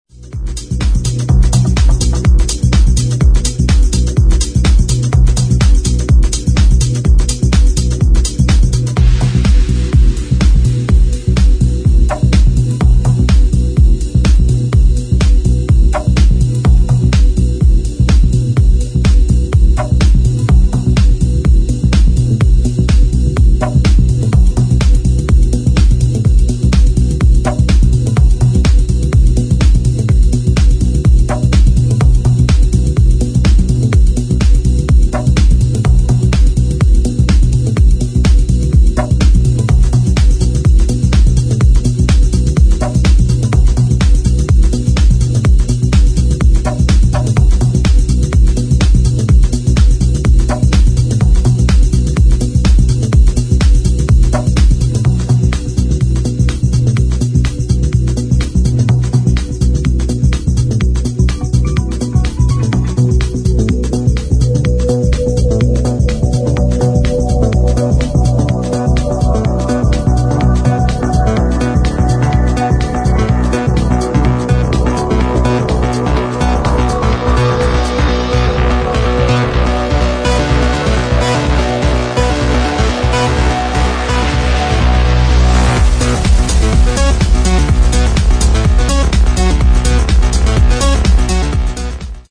[ HOUSE | TECH HOUSE ]